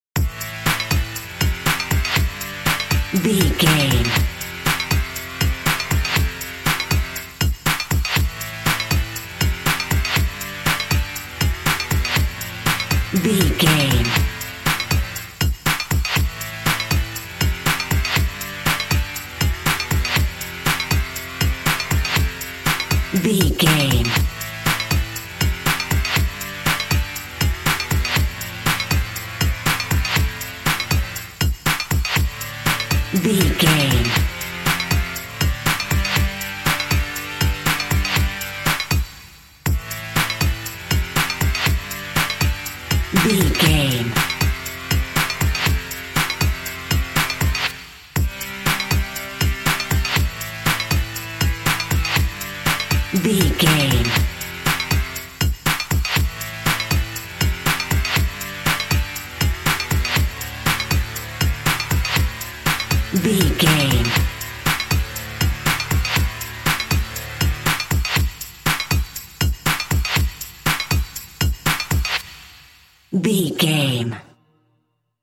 Cold Electro House.
Aeolian/Minor
cool
groovy
futuristic
calm
synthesiser
drum machine
electro house
acid house music